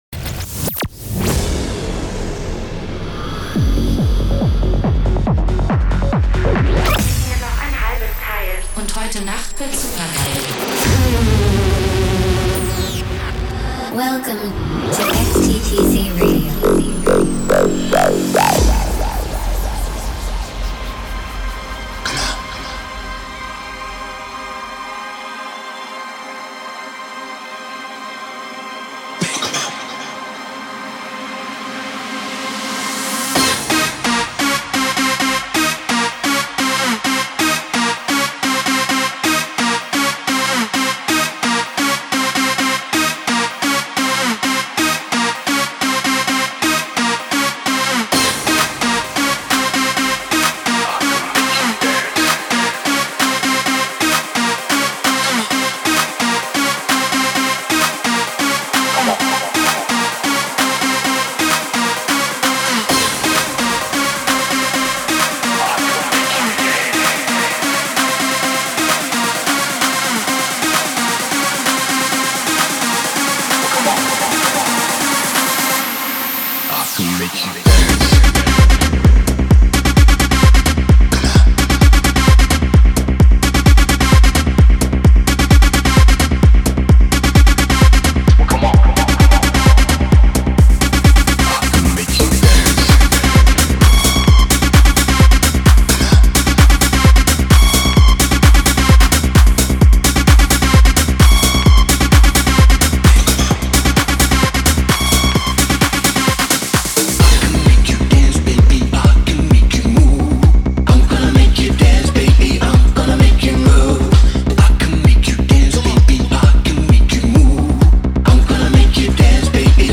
music DJ Mix in MP3 format
Genre: Techno